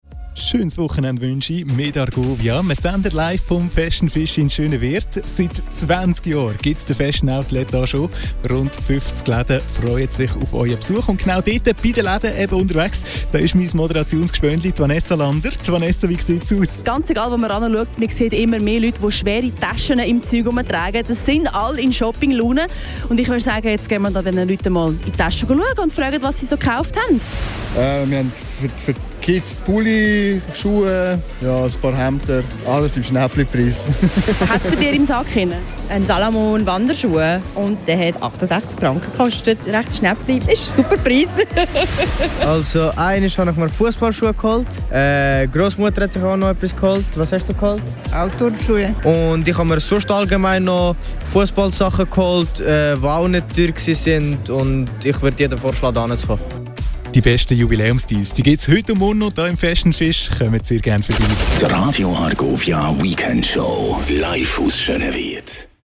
Interview mit Kunden
Radio Argovia live bei Fashion Fish 27.09.2025